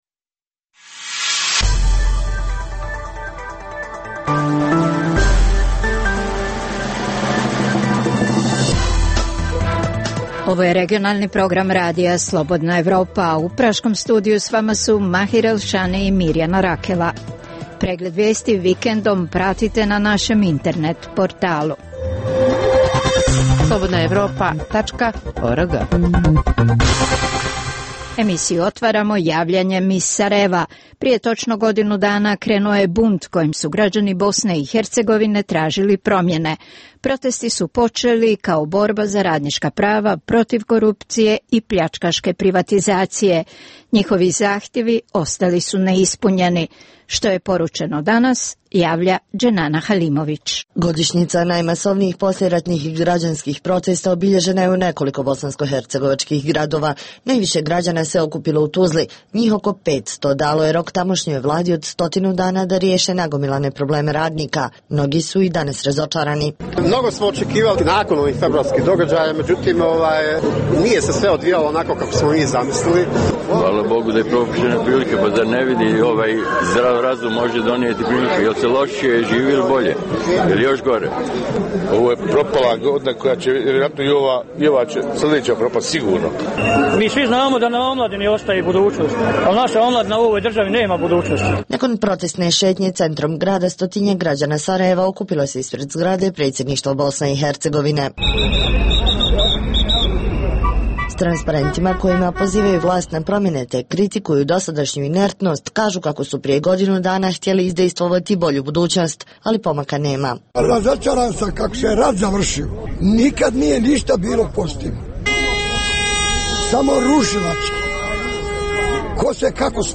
- Intervju s Ivanom Krastevom, direktorom Centra za strateške studije u Sofiji. - Reportaža s ilegalnim migrantima s Kosova.